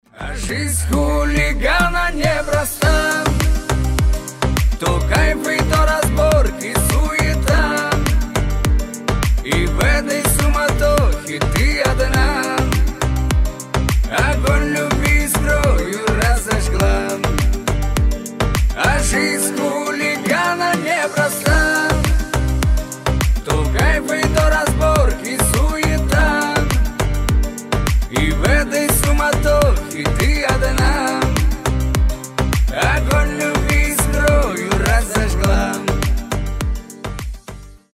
• Качество: 320, Stereo
кавказские